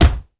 punch_general_body_impact_08.wav